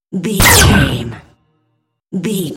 Cinematic stab hit trailer
Sound Effects
Atonal
heavy
intense
dark
aggressive
hits